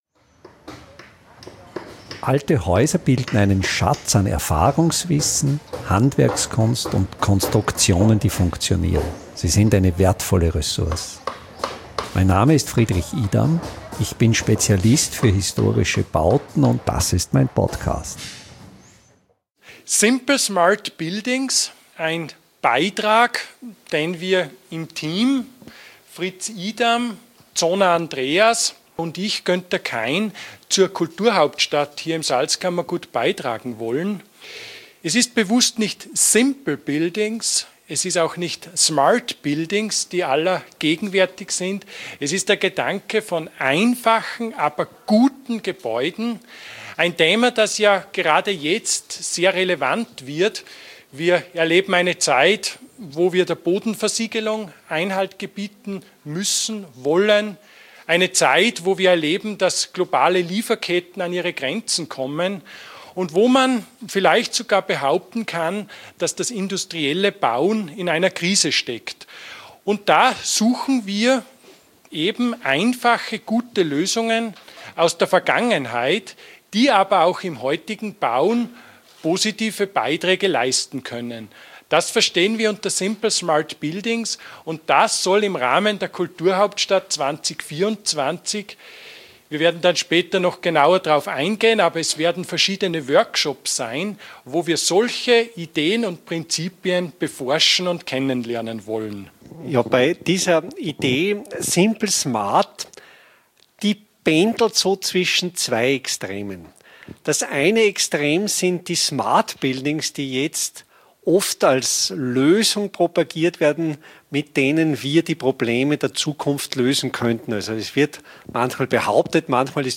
Vortrag im HandWerkHaus